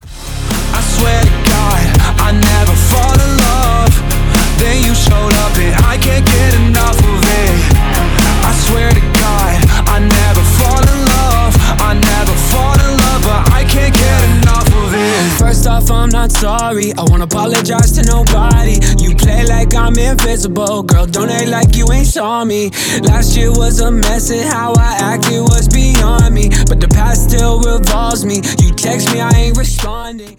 • Качество: 320 kbps, Stereo
Рок Металл
Рэп и Хип Хоп